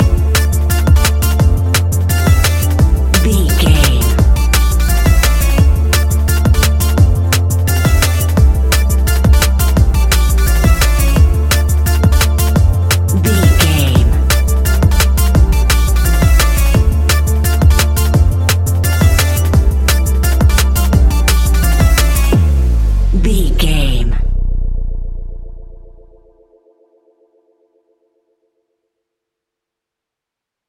Ionian/Major
techno
trance
synths
synthwave